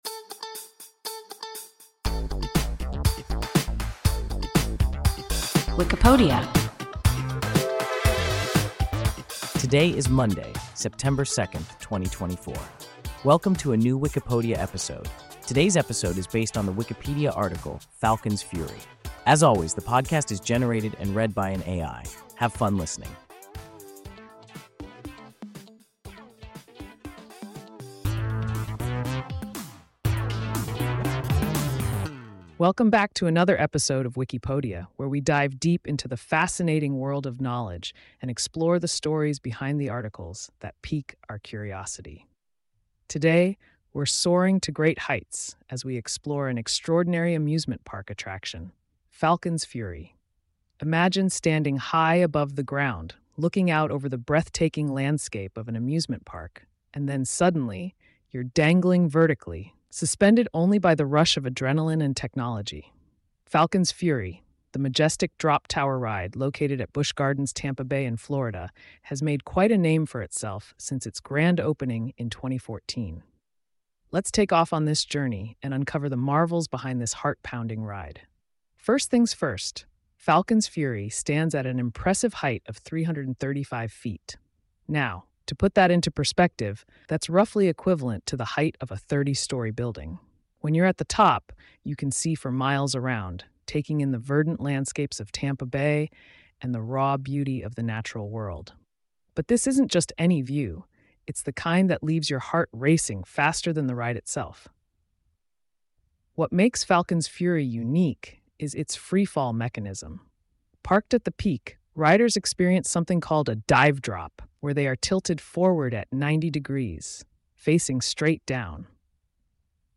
Falcon’s Fury – WIKIPODIA – ein KI Podcast